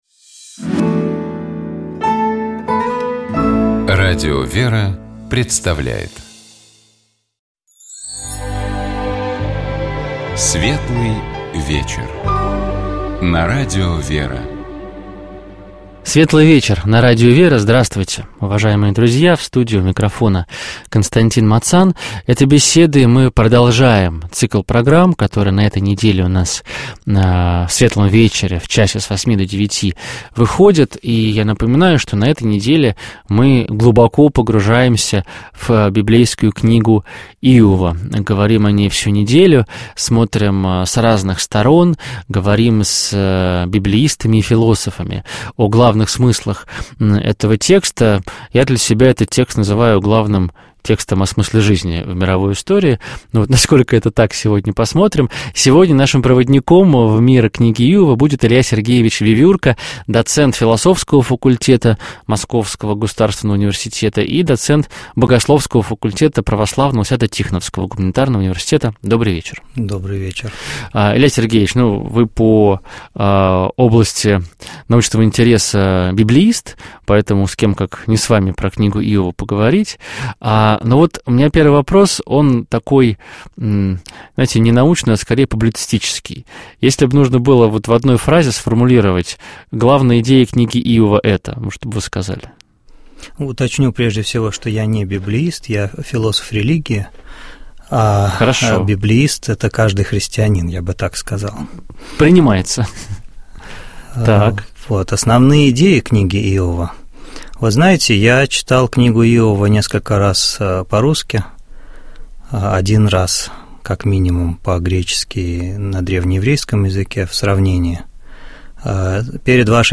Этой беседой мы продолжаем цикл из пяти программ, посвященных Книге Иова.